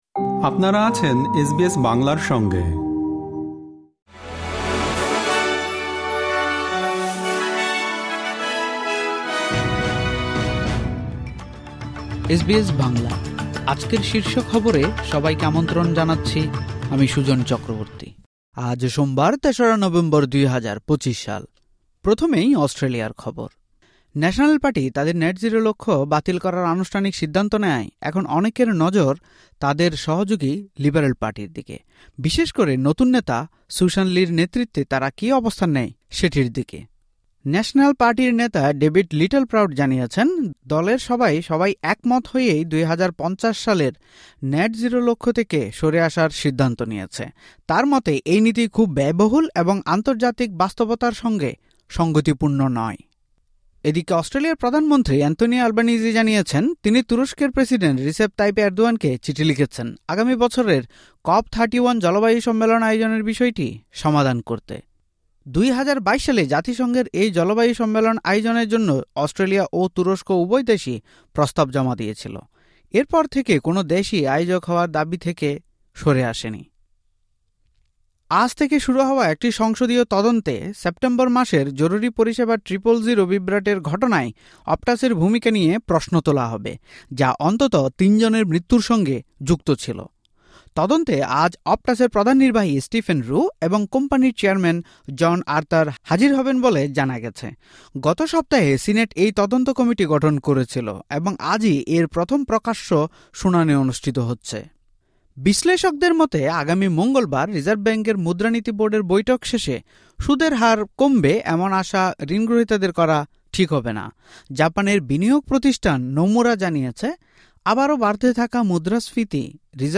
এসবিএস বাংলা শীর্ষ খবর: ৩ নভেম্বর , ২০২৫